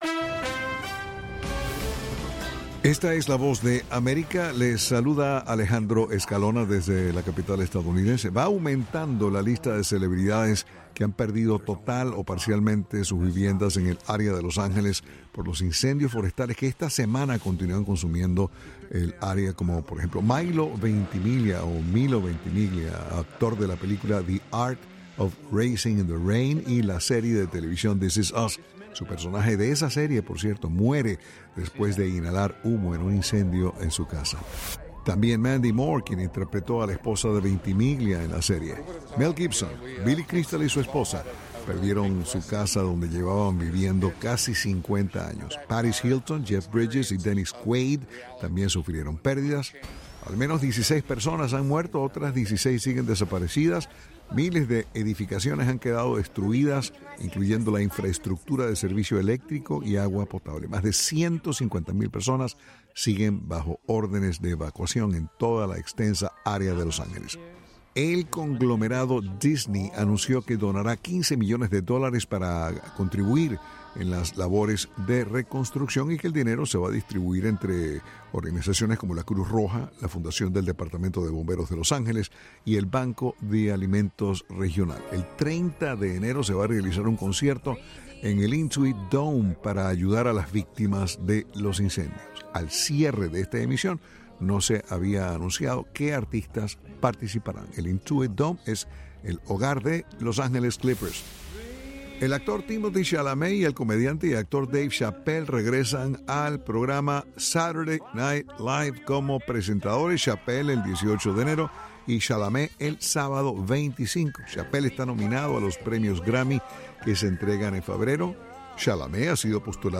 las noticias del espectáculo por la Voz de América.